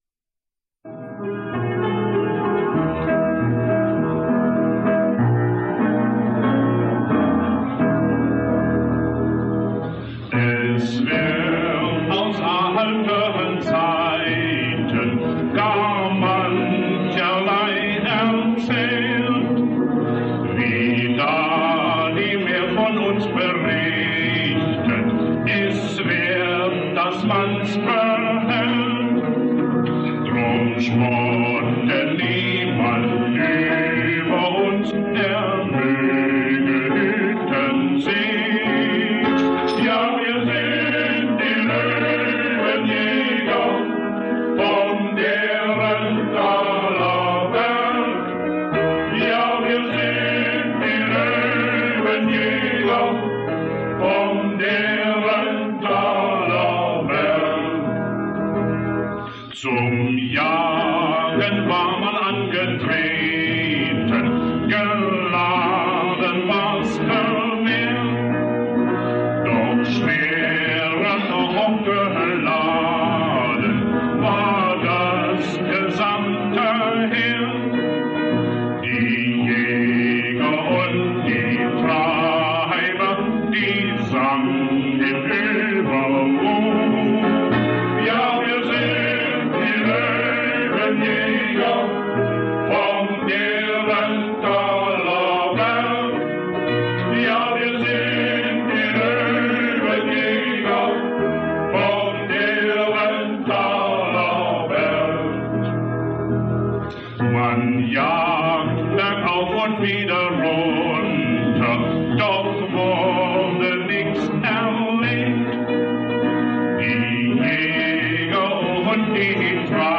Das Lied der Löwenjäger (Hymne der Derentaler) Das Löwenjägerlied können Sie hier herunterladen. Löwenjägerlied.mp3 (Melodie: Kaiserjäger-Marsch) 1.